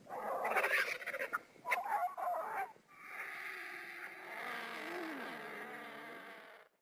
Вы можете слушать и скачивать рычание, крики, шум крыльев и другие эффекты в высоком качестве.
Звуки грифонов, издающих звуки